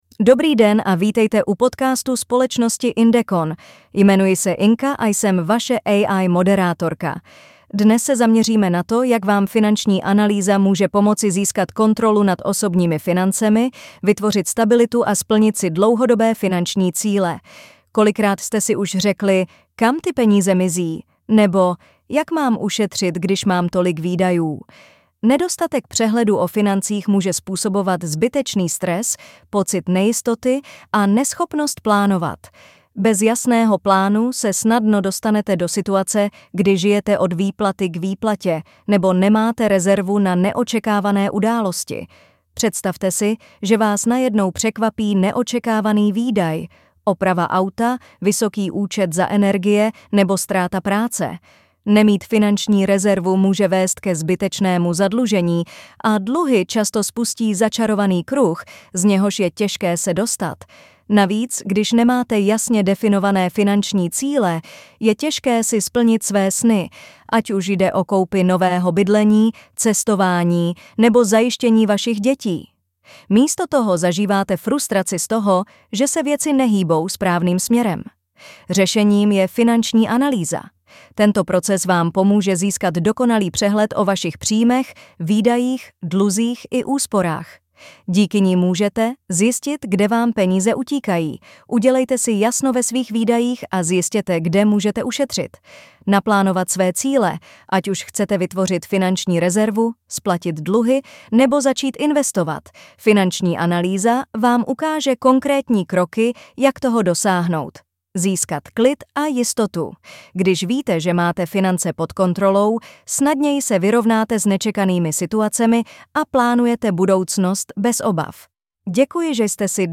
Inka, AI avatarka